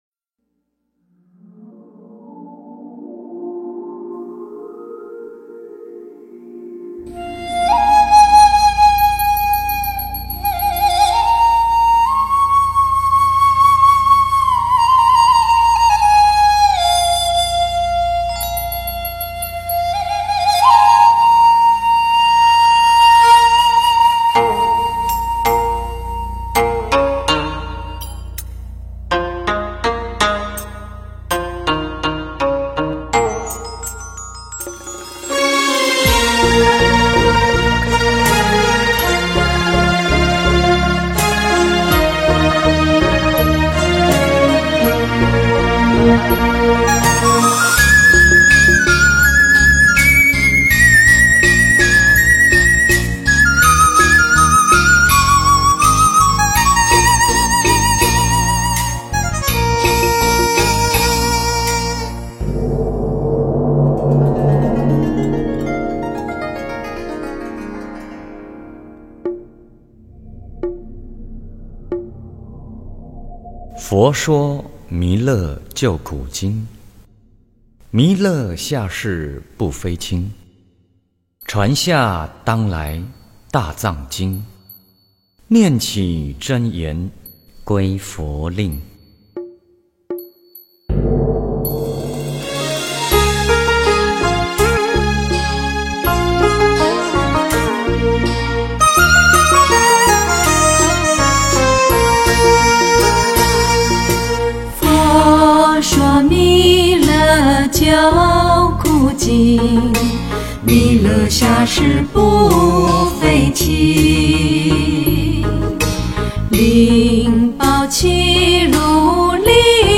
弥勒救苦真经--新韵传音 经忏 弥勒救苦真经--新韵传音 点我： 标签: 佛音 经忏 佛教音乐 返回列表 上一篇： 炉香赞.净口业真言.安土地真言.般若波罗蜜多心经.回向--新韵传音 下一篇： 南无阿弥陀佛(演唱)--新韵传音 相关文章 南无阿弥陀佛-超长版--东林佛号 南无阿弥陀佛-超长版--东林佛号...